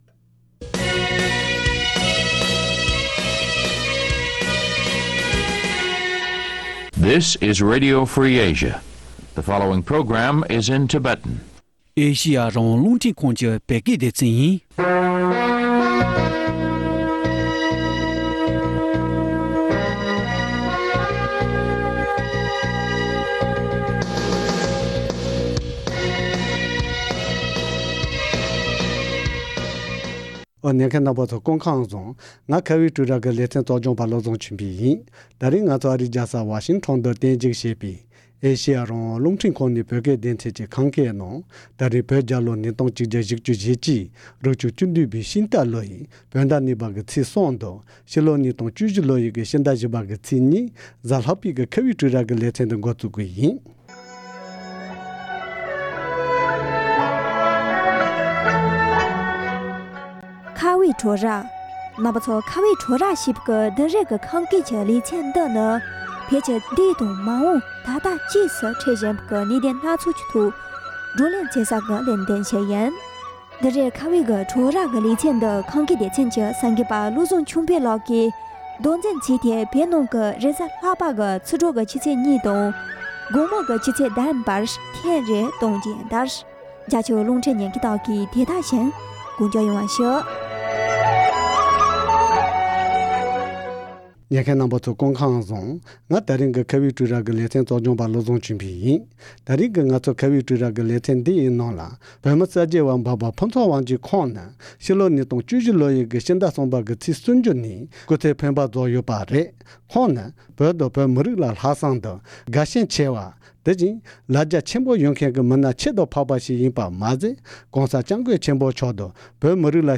༄༅༎ཐེངས་འདིའི་གཏམ་གླེང་ལེ་ཚན་ནང་དུ། བོད་མི་གསར་བརྗེ་བ་འབའ་པ་ཕུན་ཚོགས་དབང་རྒྱལ་ལགས་སྐུ་ཚེ་མ་ཟིན་པའི་ཡིད་སྐྱོའི་གནས་ཚུལ་དང་འབྲེལ་ནས་ཁོང་གི་སྐུ་ཚེའི་ལོ་རྒྱུས་དང་། དམིགས་བསལ་བོད་པའི་ཁྲོད་དང་རྒྱ་ནག་གི་དཔོན་རིགས་ཚུད་པའི་རྒྱ་ནག་གི་སྤྱི་ཚོགས་ནང་གི་ཤུགས་རྐྱེན་སོགས་གནད་དོན་ཁག་གི་ཐོག མི་སྣ་ཁག་དང་ལྷན་དབྱེ་ཞིབ་བགྲོ་གླེང་ཞུས་པར་གསན་རོགས༎